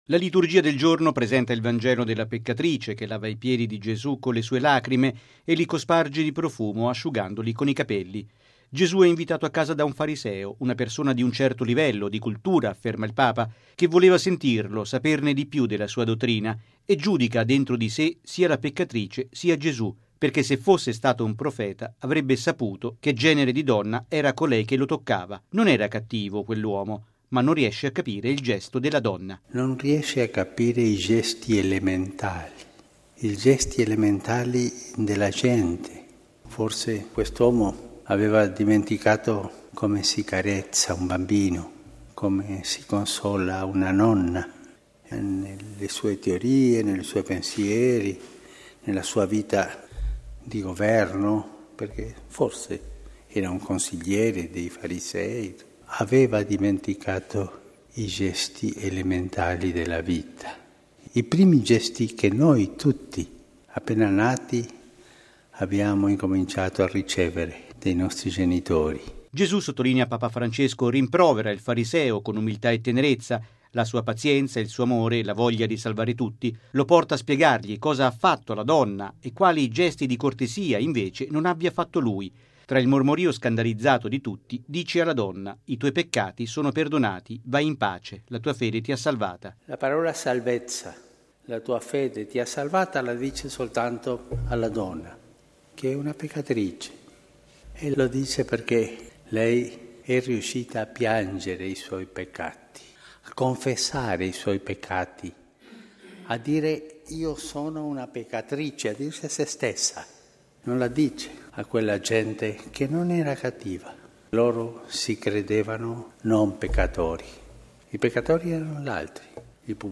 Il coraggio di riconoscerci peccatori ci apre alla carezza di Gesù, al suo perdono: è quanto ha detto il Papa nella Messa mattutina a Santa Marta. Il servizio